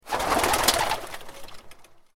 جلوه های صوتی
دانلود صدای پریدن پرنده از ساعد نیوز با لینک مستقیم و کیفیت بالا
برچسب: دانلود آهنگ های افکت صوتی انسان و موجودات زنده